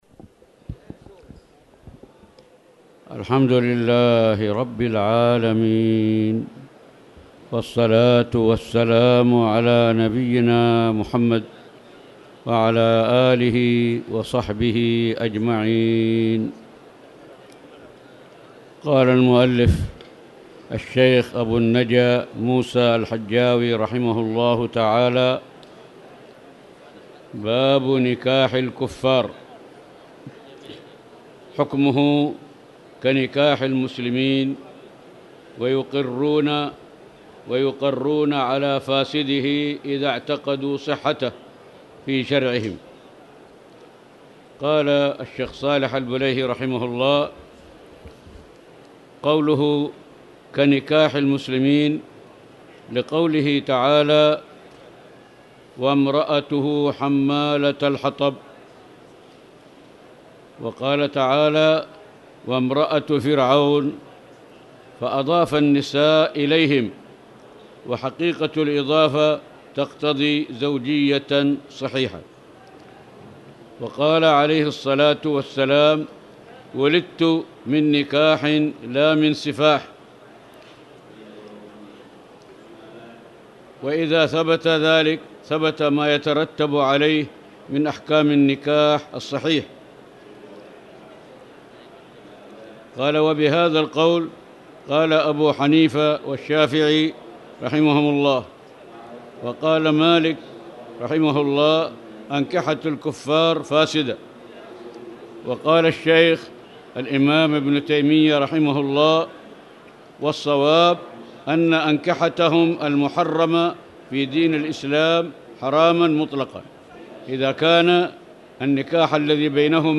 تاريخ النشر ١٧ رمضان ١٤٣٧ المكان: المسجد الحرام الشيخ